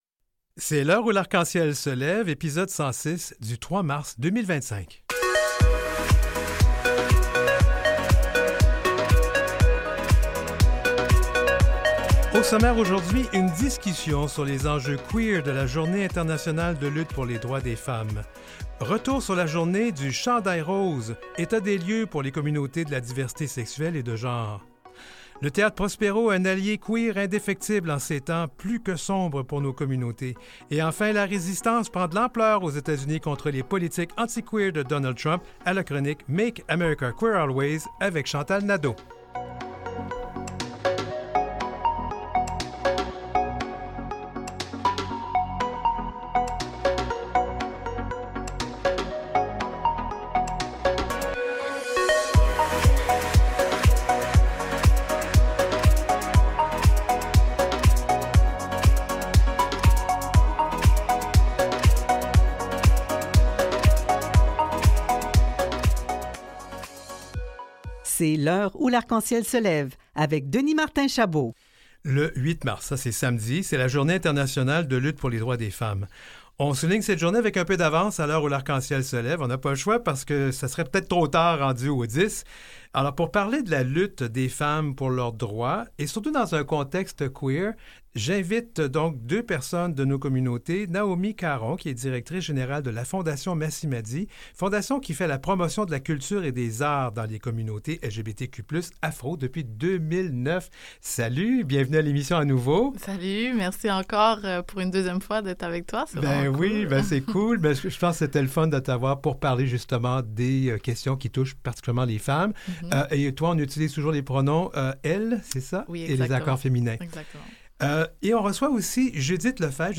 Un espace hebdomadaire inclusif, des échanges ouverts, des invité.e.s au coeur de l’actualité et des débats, une heure dédiée à l’actualité et aux enjeux touchant les personnes des communautés de la diversité des orientations sexuelles et des affirmations de genre. L’heure où l’arc-en-ciel se lève, la première émission radiophonique de langue française pancanadienne dédiée à la diversité 2ELGBTQIA+.